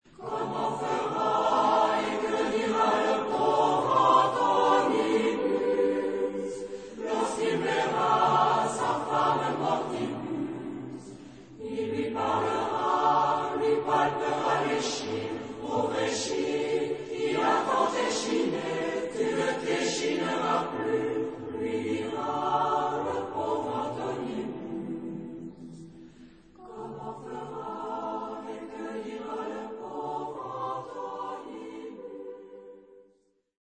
Epoque: 20th century
Genre-Style-Form: Secular ; Drinking song
Type of Choir: SATB  (4 mixed voices )
Tonality: E minor